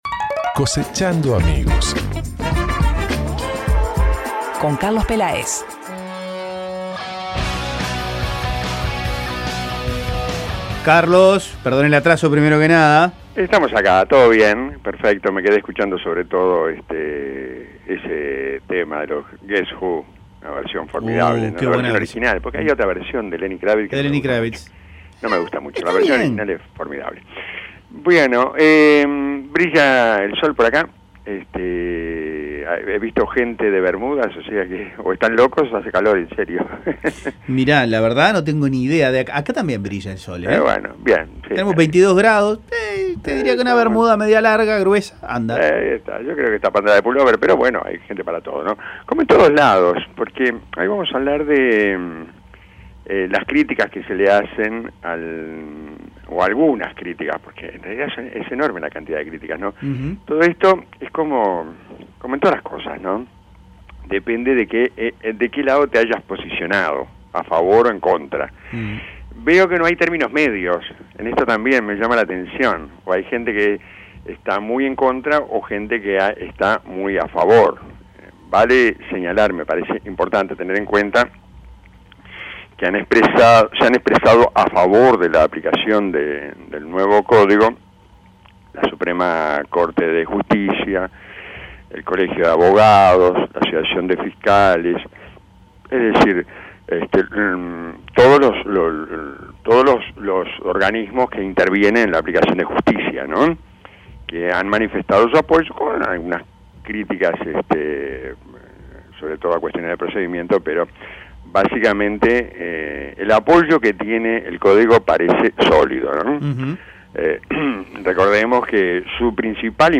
En el siguiente informe comentamos esos cuestionamientos.